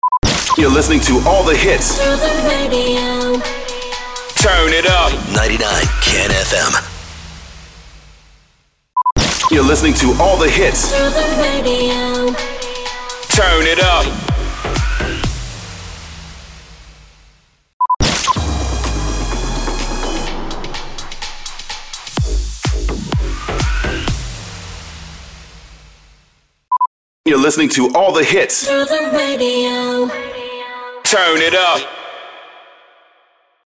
275 – SWEEPER – THROUGH THE RADIO
275-SWEEPER-THROUGH-THE-RADIO.mp3